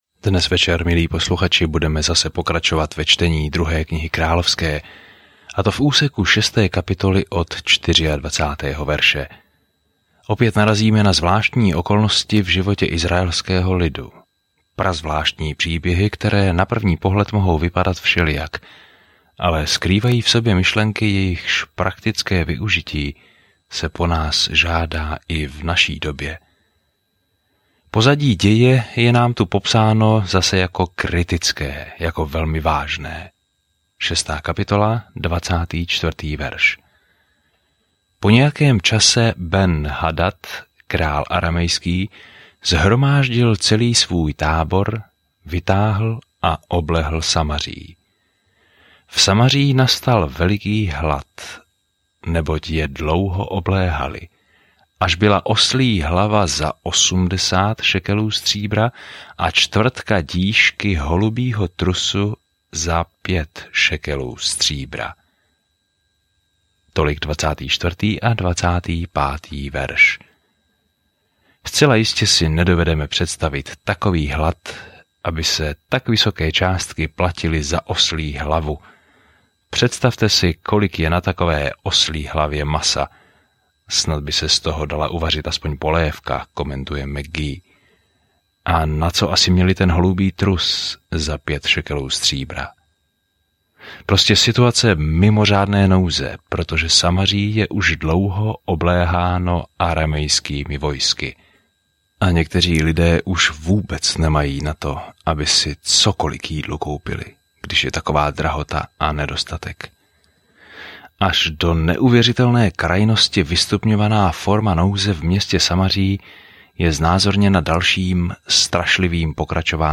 Písmo 2 Královská 6:24-33 2 Královská 7 Den 5 Začít tento plán Den 7 O tomto plánu Kniha Druhých králů vypráví, jak lidé ztratili Boha z očí a jak na ně nikdy nezapomněl. Denně procházejte 2 králi a poslouchejte audiostudii a čtěte vybrané verše z Božího slova.